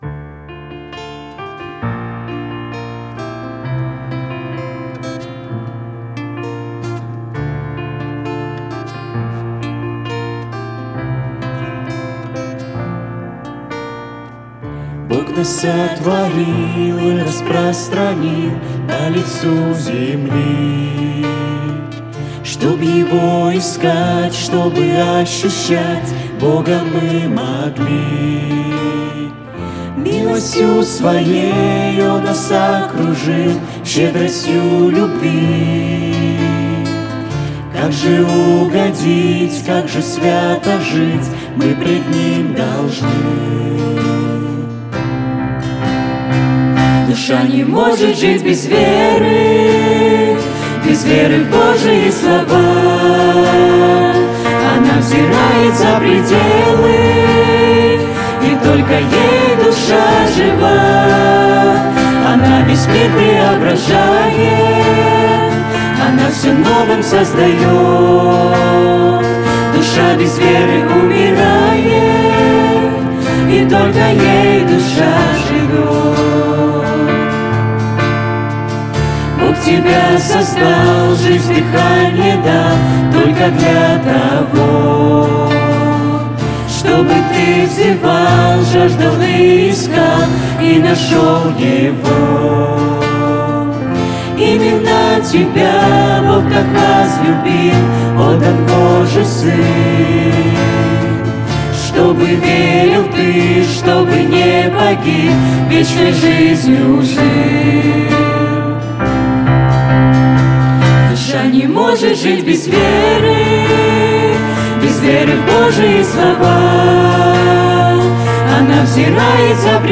клавиши, вокал
ударные
скрипка
гитара